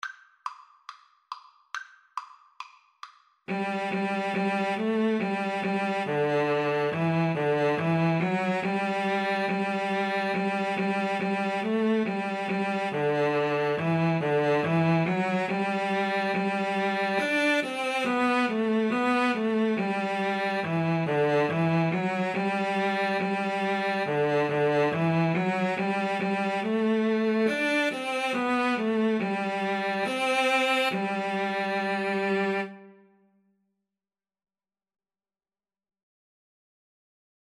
Play (or use space bar on your keyboard) Pause Music Playalong - Player 1 Accompaniment reset tempo print settings full screen
G major (Sounding Pitch) (View more G major Music for Cello Duet )
2/2 (View more 2/2 Music)
Cello Duet  (View more Easy Cello Duet Music)